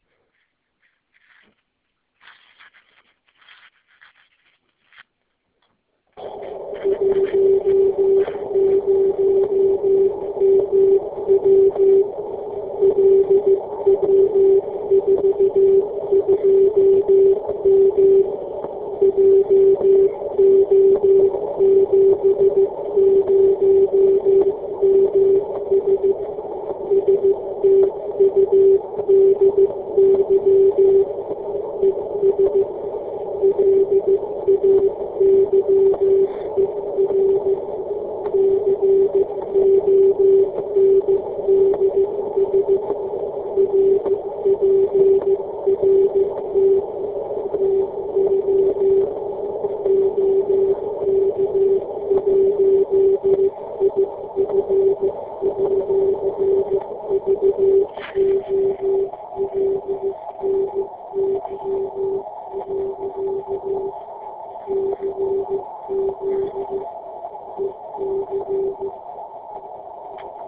10 mW Beacon OK1IF ( *.wav 114 kB)